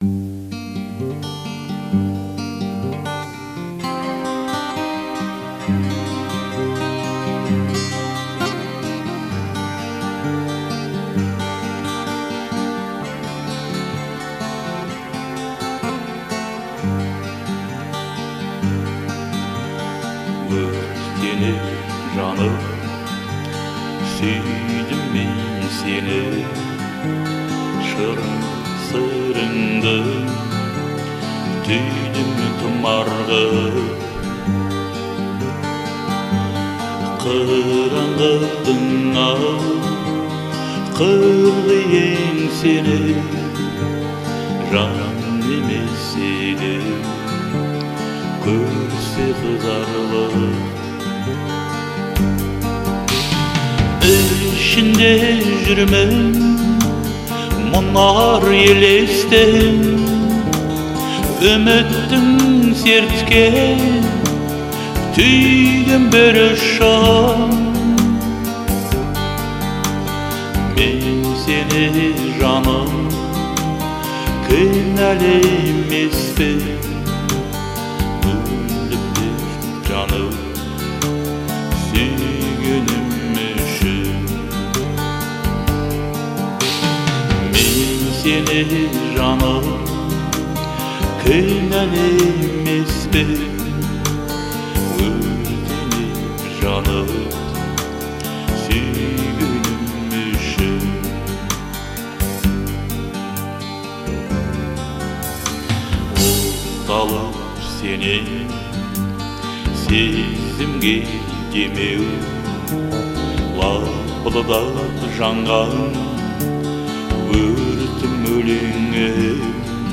это трогательная казахская песня в жанре поп-фолк